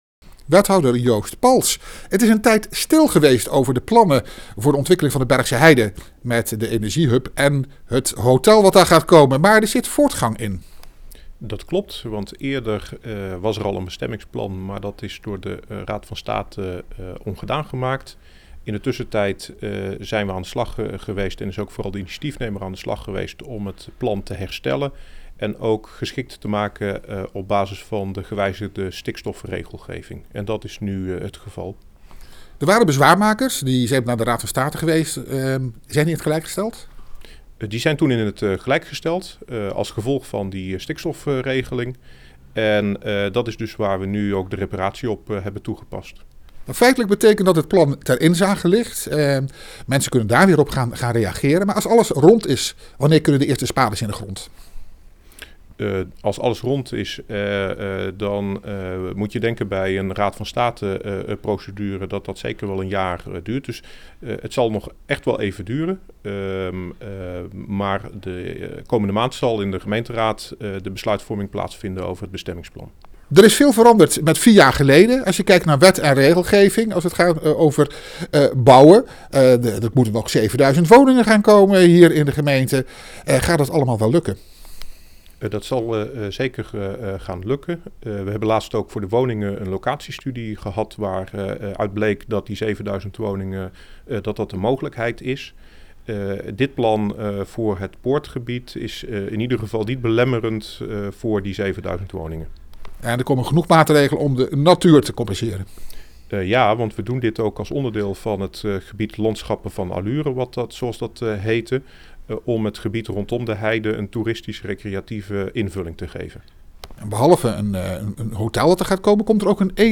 Wethouder Joost Pals over het aangepaste bestemmingsplan Poortgebied Bergsche Heide.